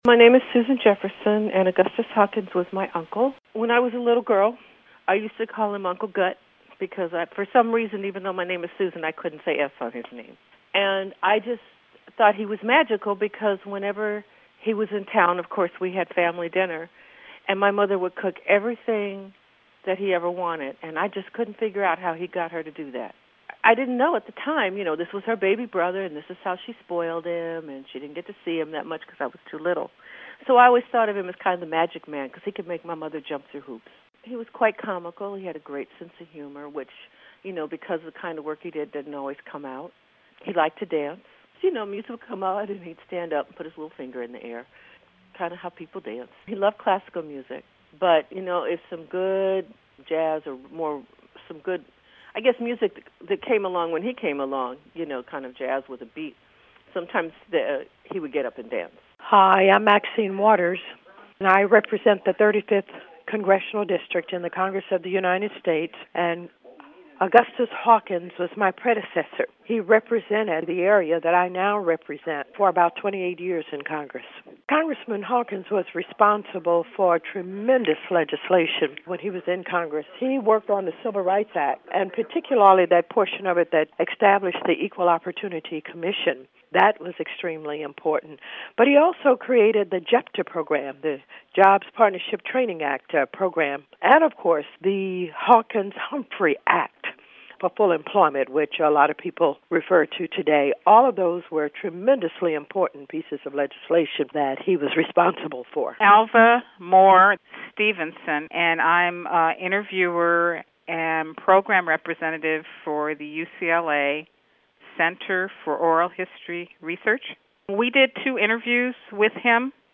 A profile of Congressman Augustus Hawkins by those who knew and worked with him.